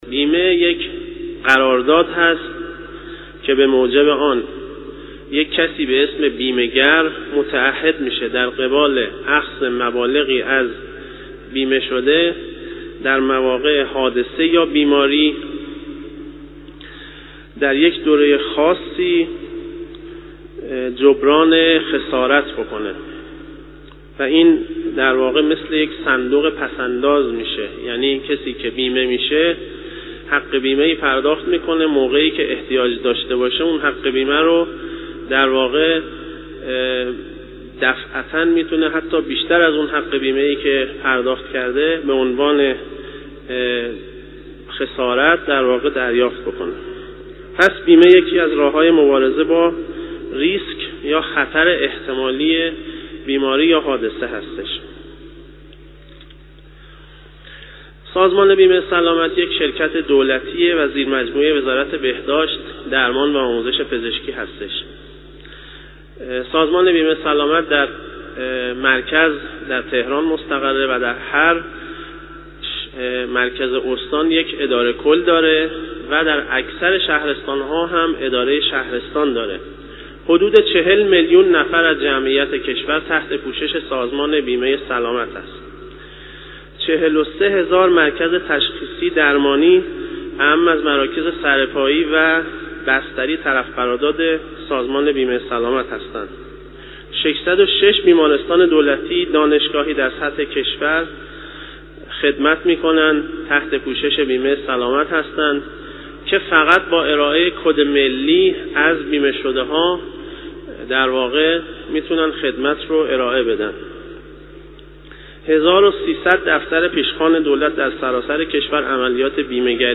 در سخنرانی پیش از خطبه های نماز جمعه قم که در مصلای قدس برگزار شد